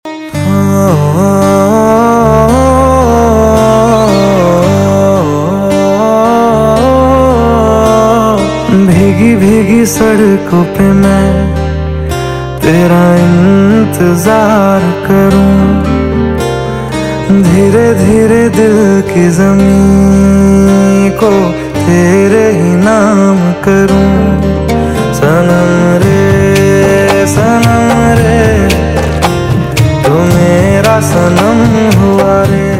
CategoryHindi Ringtones